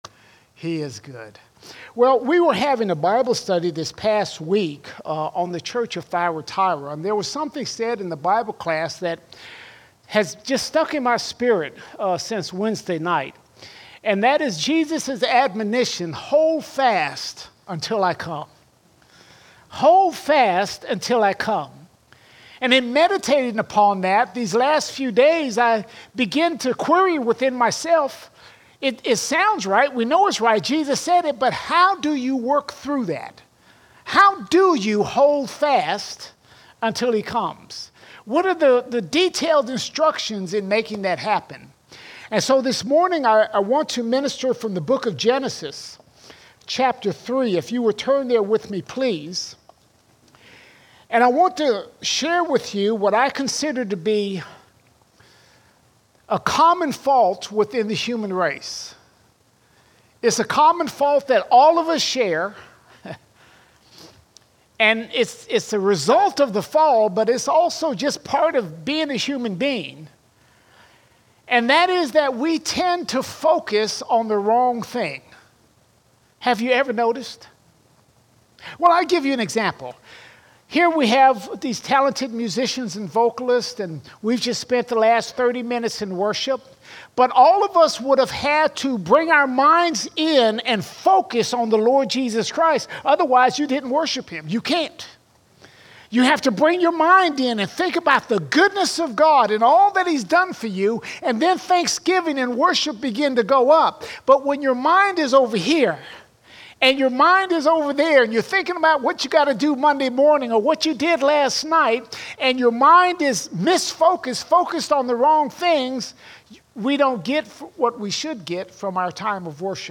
30 July 2024 Series: Sunday Sermons Topic: spiritual growth All Sermons A Faulty Focus A Faulty Focus We have a focus problem when we value the things happening in this world more than the things of God.